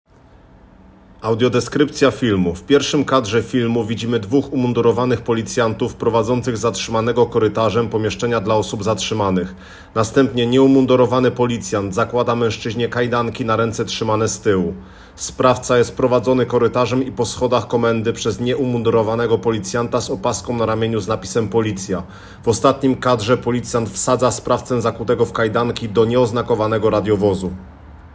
Nagranie audio Audiodeskrypcja_filmu.m4a